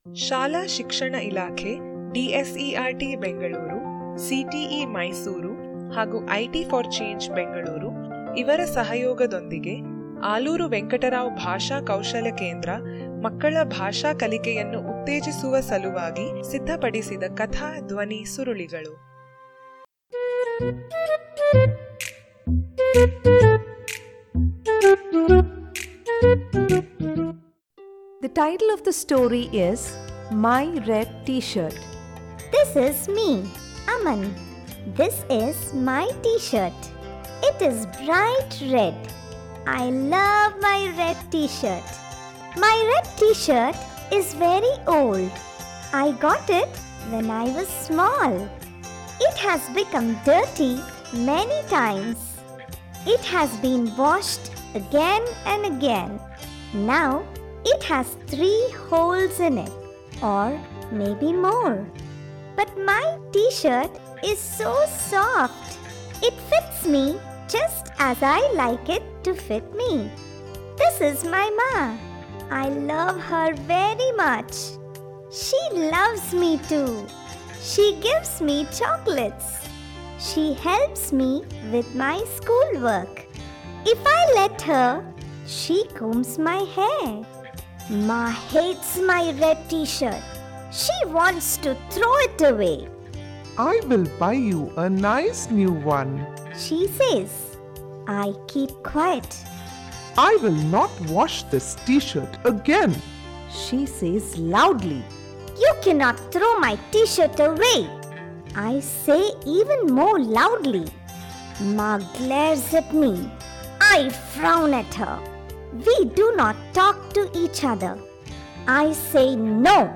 My Red T Shirt - Audio Story Activity Page